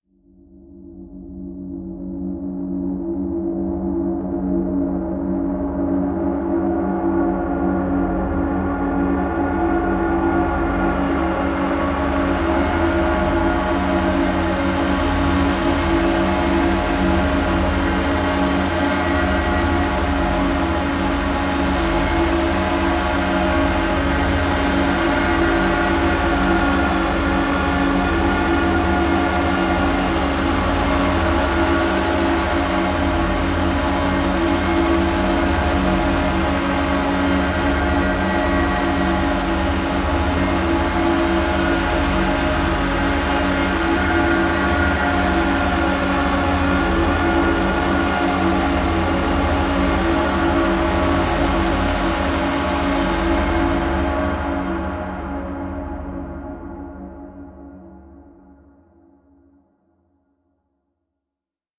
tension horror